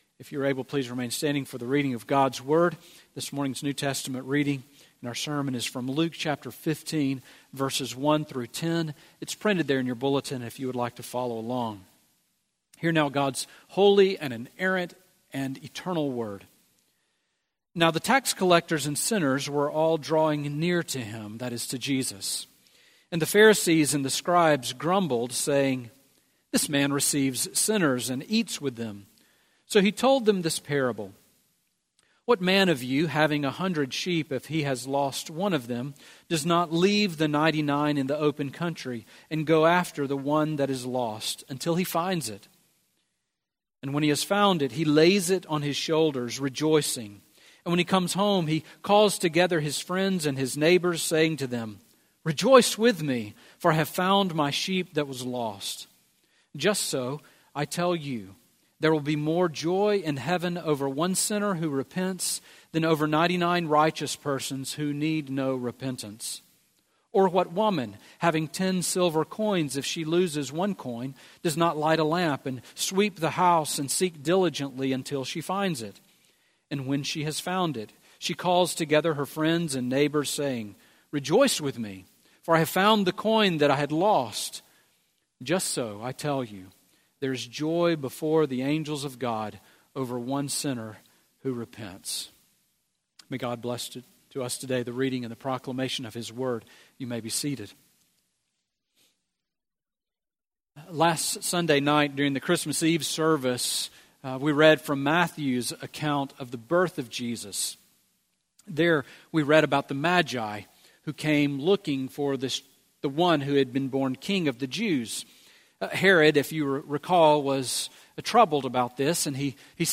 Sermon Audio from Sunday
Sermon on Luke 15:1-10 from December 31